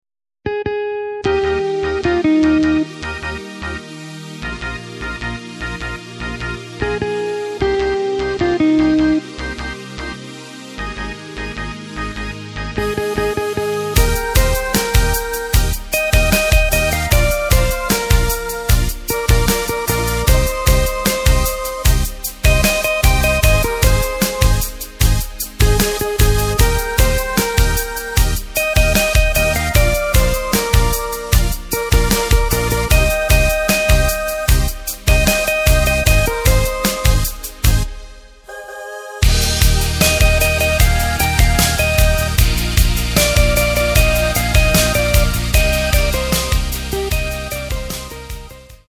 Takt:          4/4
Tempo:         151.00
Tonart:            Eb
Pop (Austropop) aus dem Jahr 2016!